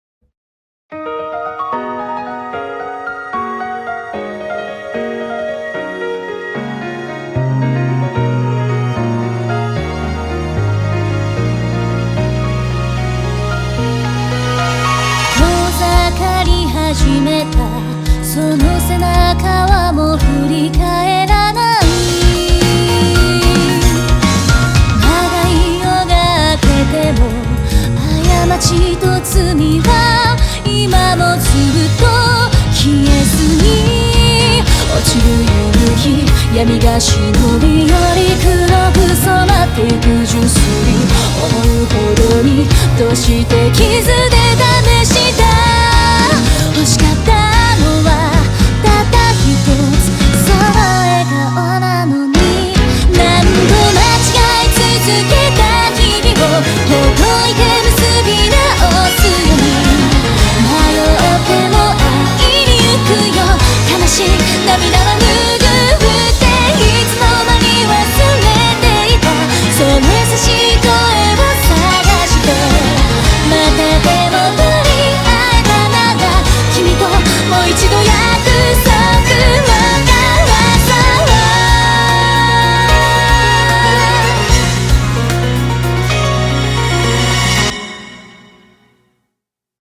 BPM112
Audio QualityCut From Video